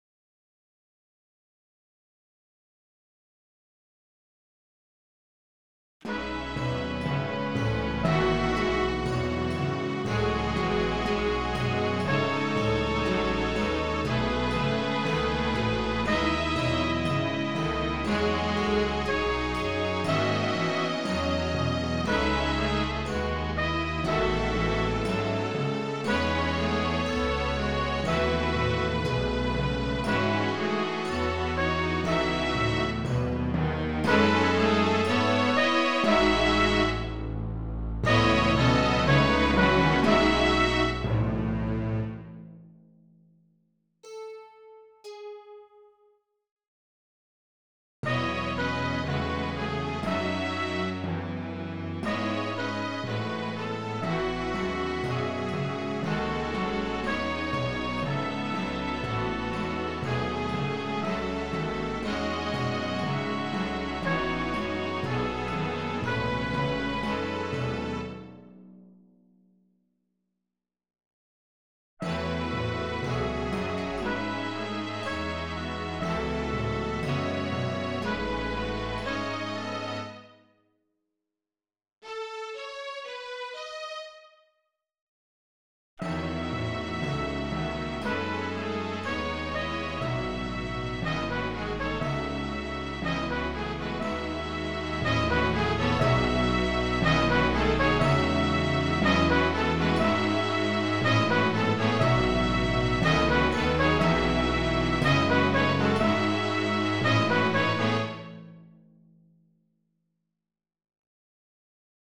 From my Third most recent original musical composition Symphony; Duisburg Somer.